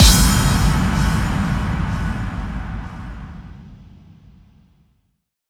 VEC3 FX Reverbkicks 28.wav